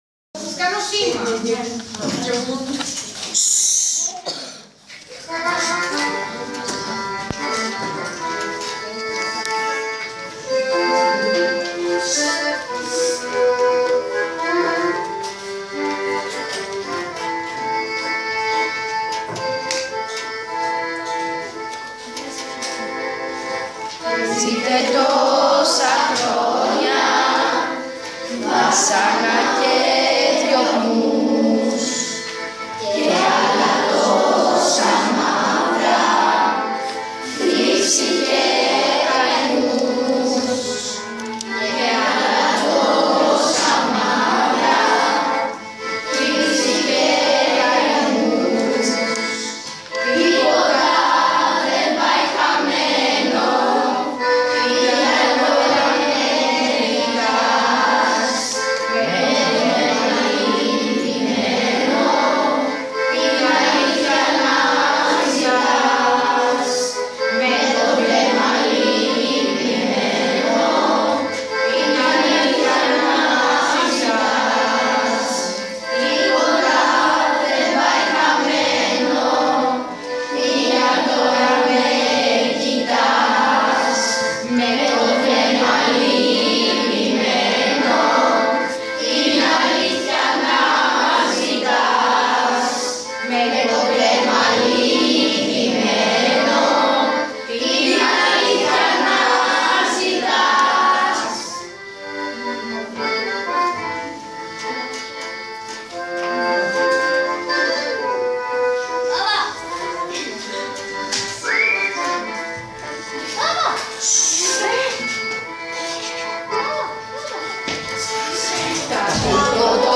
ΤΟ ΤΡΑΓΟΥΔΙ ΤΗΣ ΣΤ’ ΤΑΞΗΣ ΓΙΑ ΤΟΥΣ ΠΡΟΣΦΥΓΕΣ